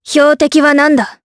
Tanya-vox-select_jp.wav